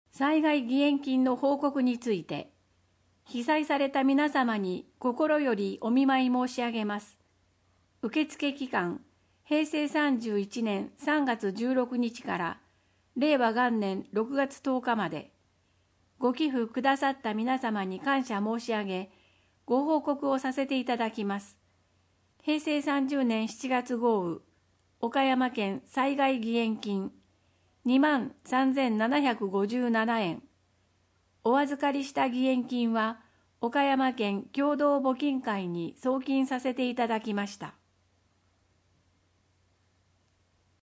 くらしき社協だより第81号 音訳版